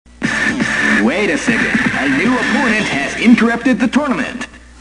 The announcer's weird rambling speech while trying to select a fighting method.